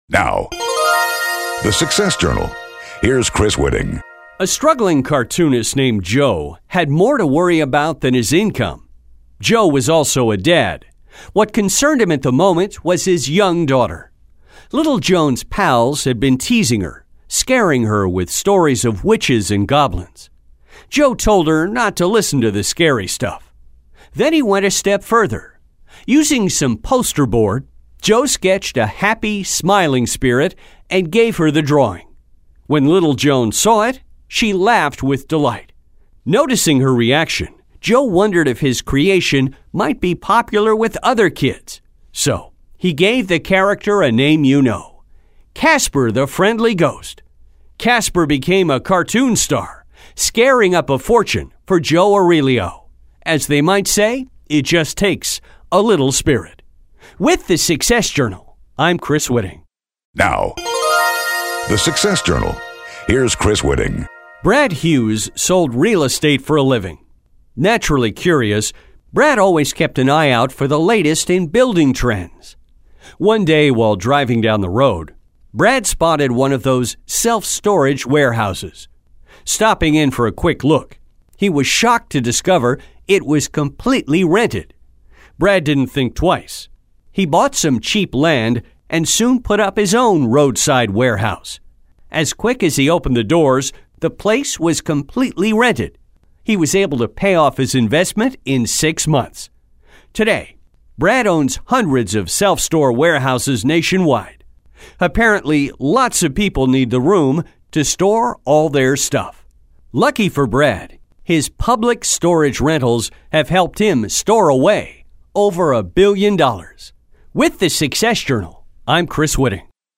THE SUCCESS JOURNAL is the daily :60 feature that tells the little-known stories of the people behind famous brand names and popular products.
Radio’s favorite success and business feature…now celebrating over 4,500 broadcasts on over 100 US stations, and American Forces Radio worldwide.